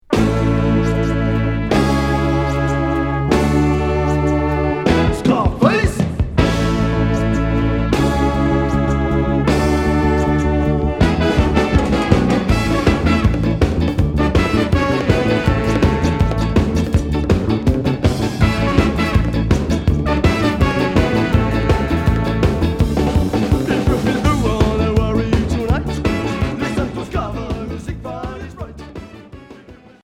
Ska Premier 45t retour à l'accueil